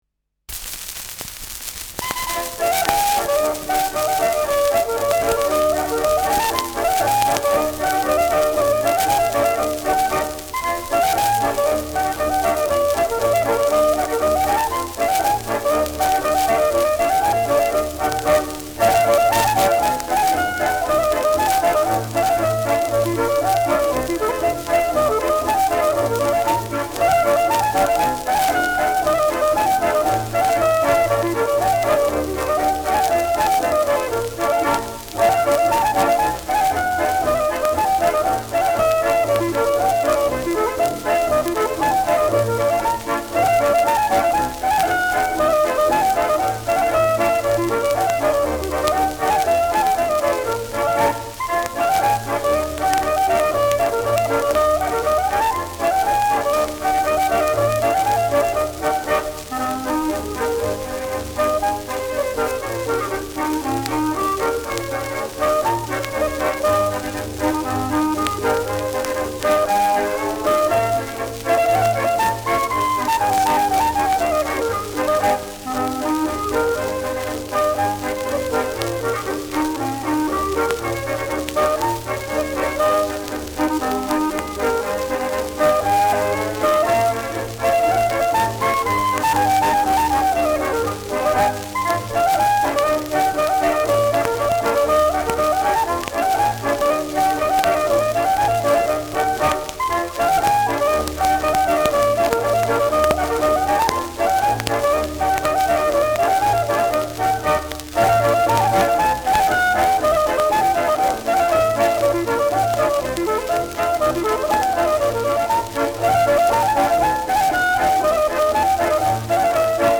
Schellackplatte
[Berlin?] (Aufnahmeort)
Ländlerkapelle* FVS-00018